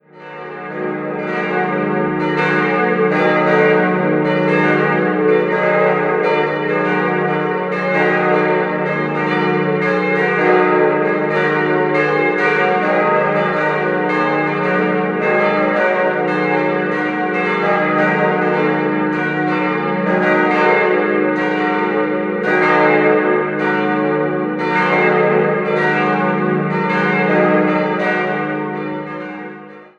Der Innenraum erhielt dabei nur ein sehr schlichtes Gewand. 4-stimmiges Geläut: es'-ges'-as'-b' Die Glocken wurden 1959 von der Gießerei Schilling in Heidelberg gegossen.